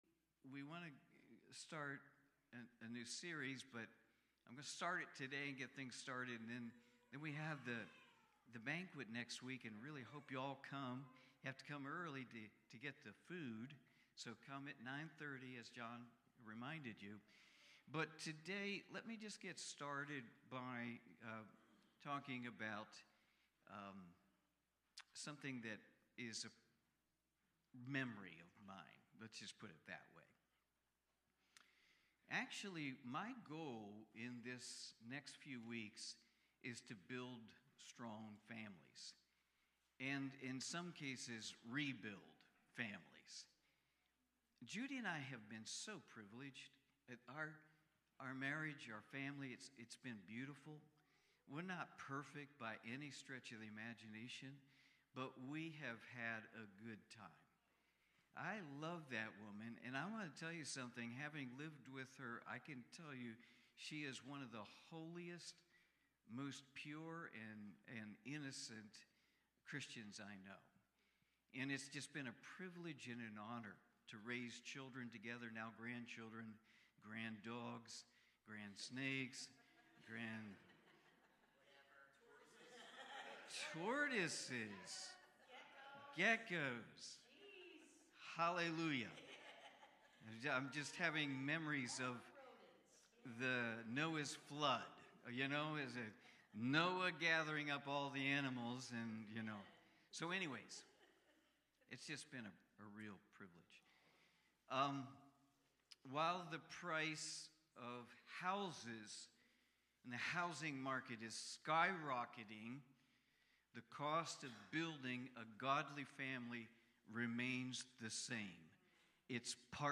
Philippians Watch Listen Save Cornerstone Fellowship Sunday morning service, livestreamed from Wormleysburg, PA.